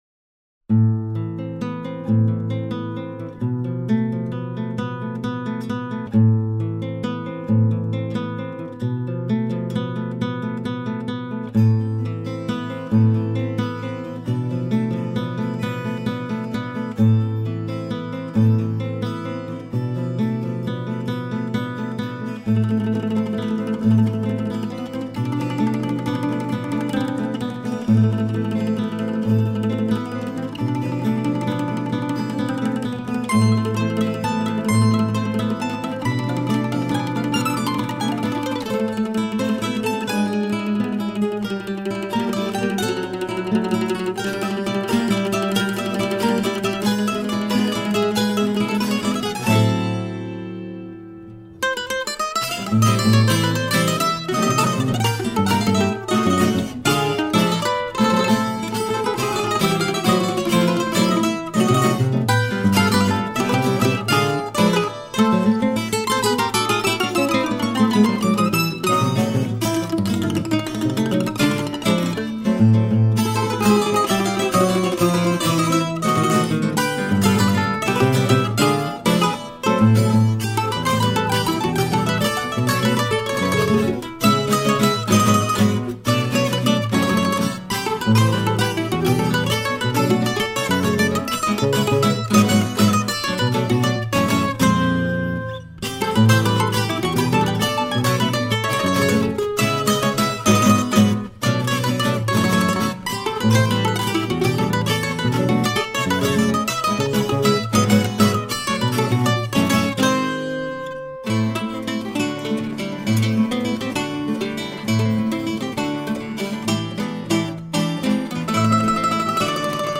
Cuarteto Instrumental Colombiana